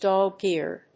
/ˈdɑgird(米国英語), ˈdɑ:gi:rd(英国英語)/
アクセントdóg‐èared